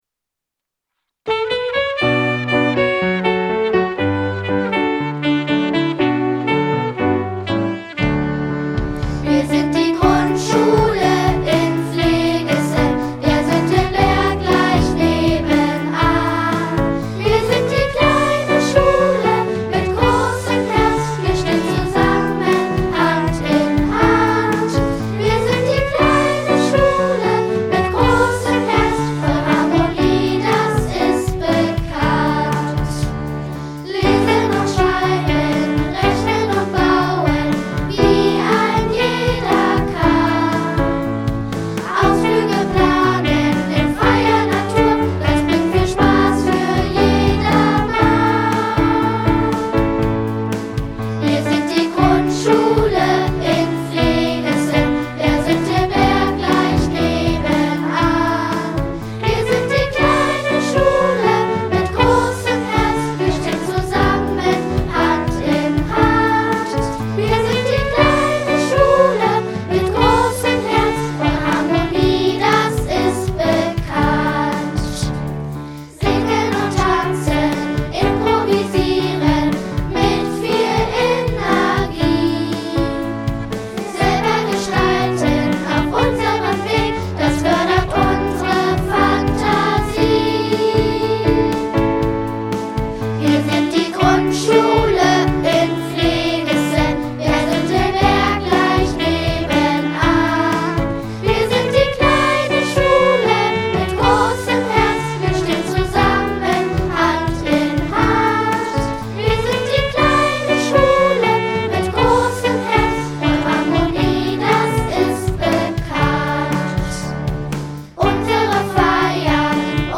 eingesungen von unseren Schülerinnen und Schülern